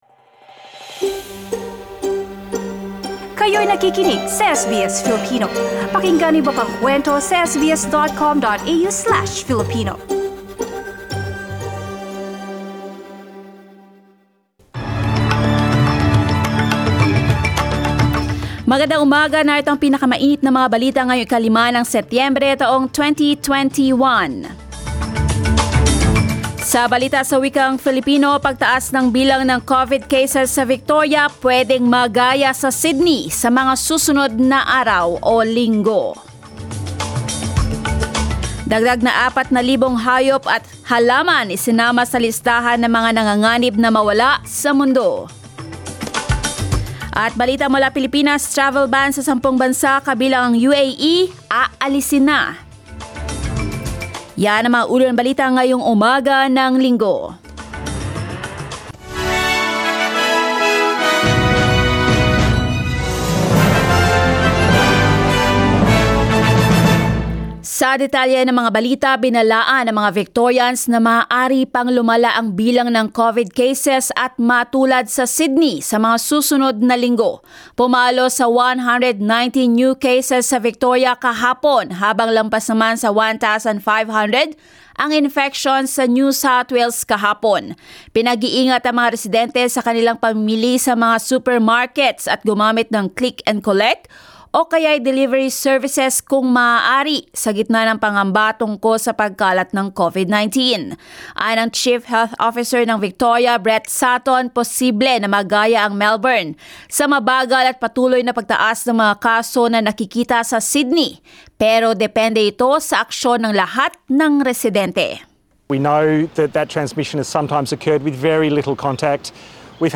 SBS News in Filipino, Sunday 5 September